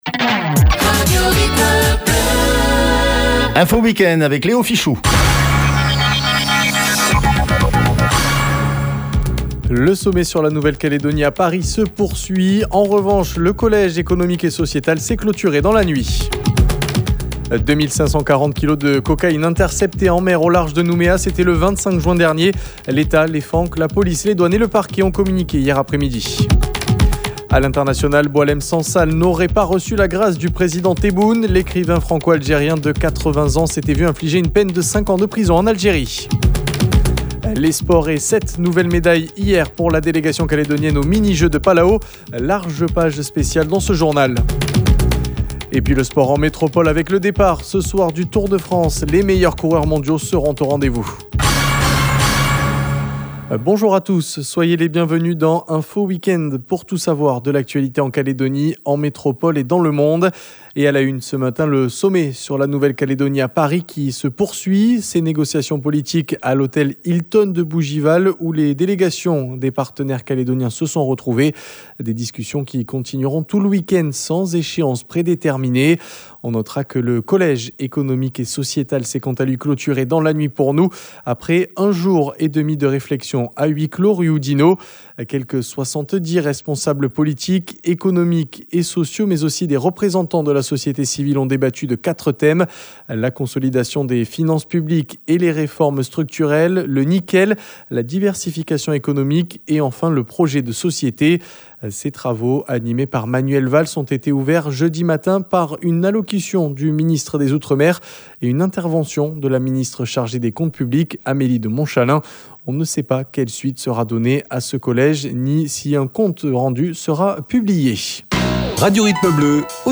JOURNAL : WEEK-END SAMEDI MATIN 05/07/25